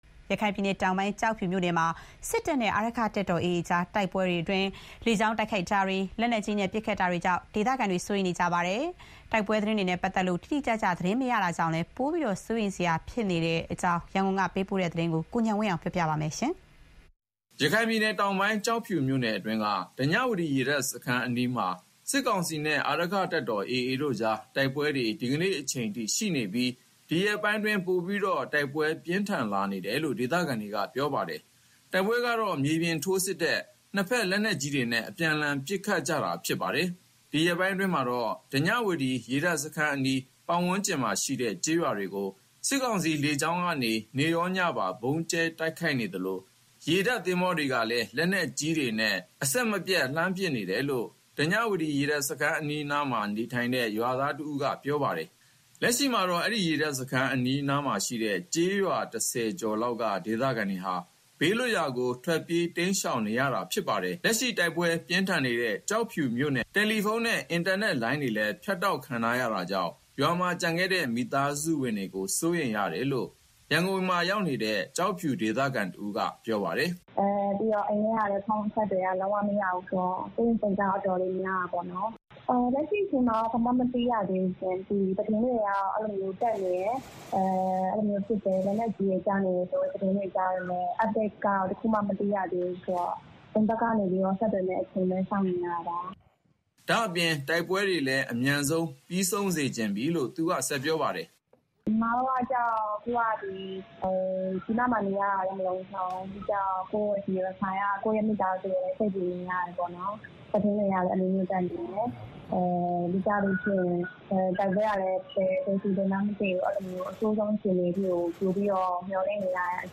ရခိုင်ပြည်နယ် တောင်ပိုင်း ကျောက်ဖြူမြို့နယ်မှာ စစ်တပ်နဲ့ အာရက္ခတပ်တော် AA တို့ကြား တိုက်ပွဲမှာ လေကြောင်း တိုက်ခိုက်မှုတွေ၊ လက်နက်ကြီးနဲ့ ပတ်ခတ်မှုတွေကြောင့် ဒေသခံတွေ စိုးရိမ်နေကြပါတယ်။ တိုက်ပွဲသတင်းတွေနဲ့ပတ်သက်လို့ တိတိကျကျ သတင်း မသိရတာကြောင့်လည်း ပိုပြီး စိုးရိမ်စရာဖြစ်နေကြတဲ့ အခြေအနေအကြောင်း ရန်ကုန်က ပေးပို့တဲ့သတင်းဖြစ်ပါတယ်။